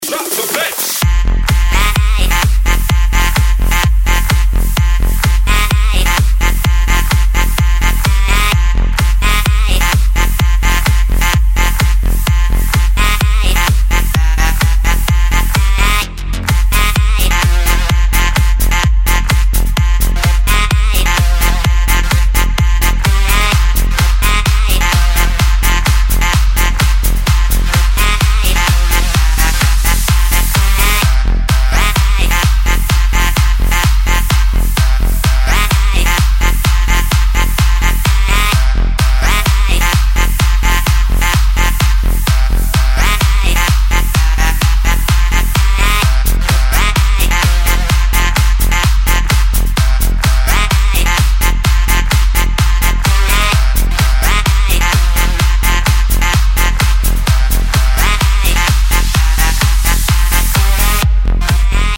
• Качество: 192, Stereo
Датч Хаус что надо и по всем правилам!